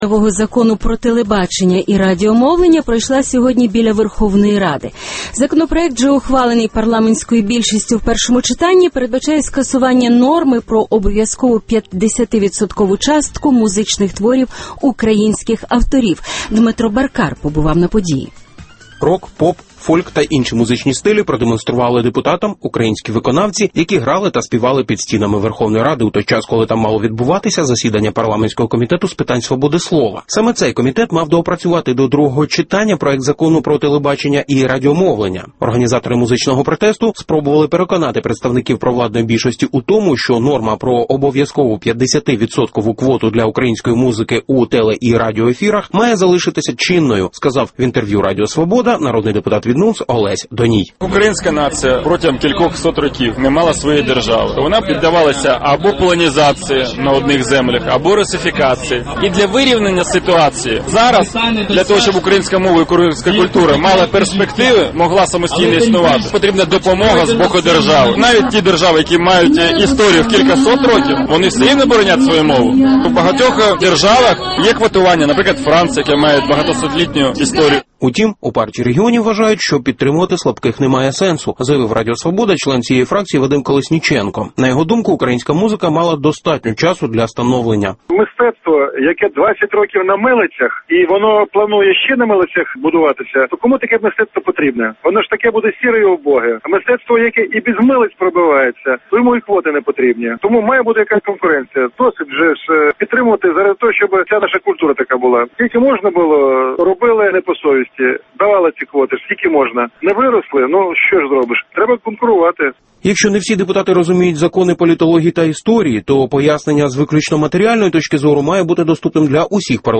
Біля Верховної Ради відбувся музичний протест